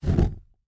FlameSuctionCan HI028202
Flame Suction In Can